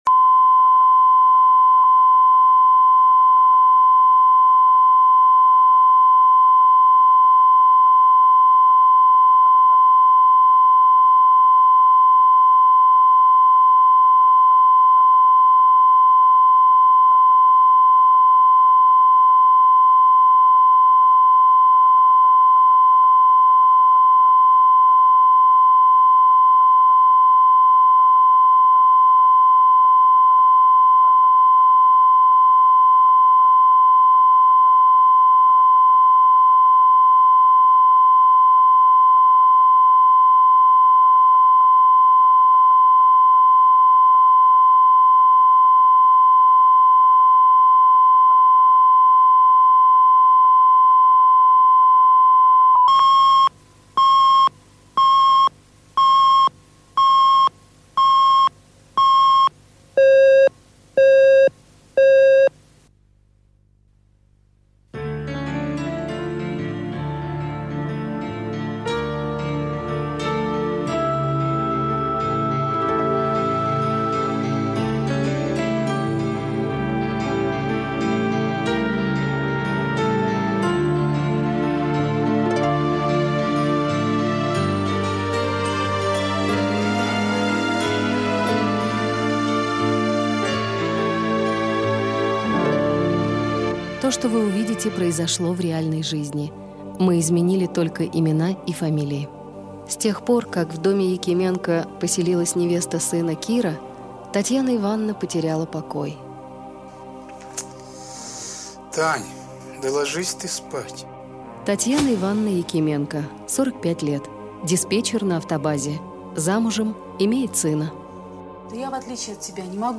Аудиокнига Невестка | Библиотека аудиокниг